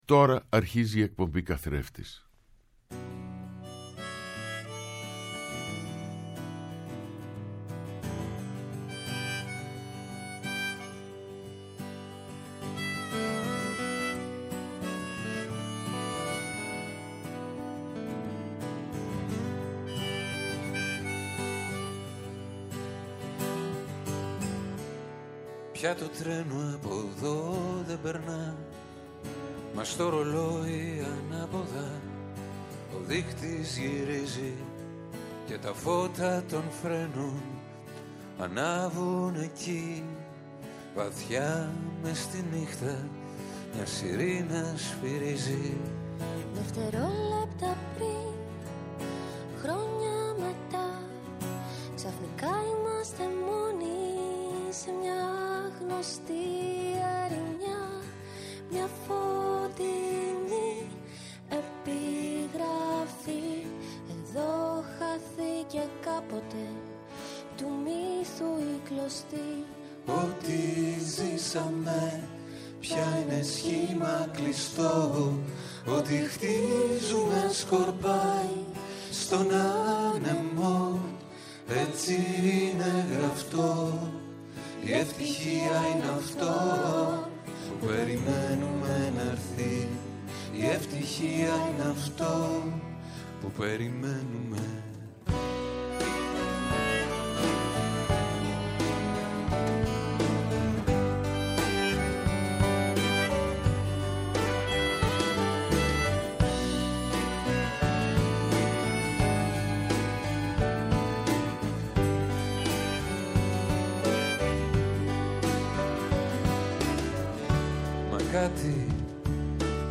Καλεσμένοι τηλεφωνικά στην σημερινή εκπομπή